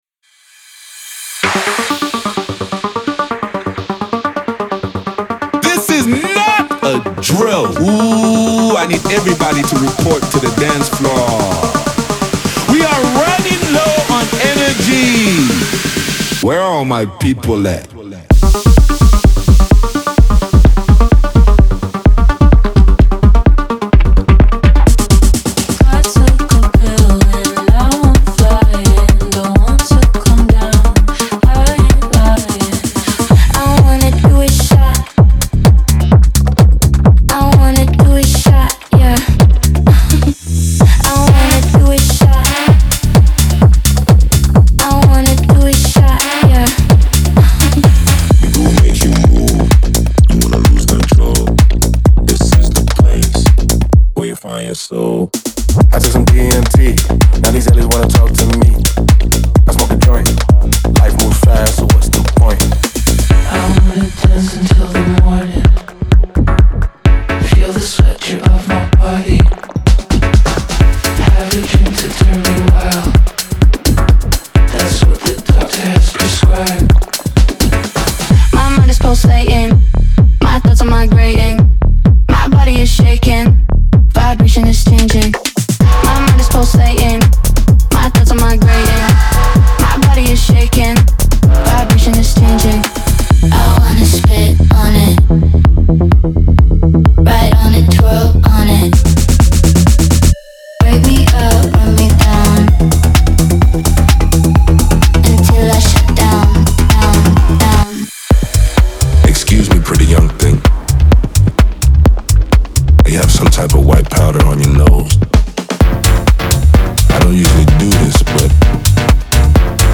Genre:Tech House
力強くパンチのあるドラムサウンド
ローリングでグルーヴィーなベースループ
アナログに着想を得たシンセテクスチャ
オーディオデモは大きく、コンプレッションされ、均一に聞こえるよう処理されています。
128 BPM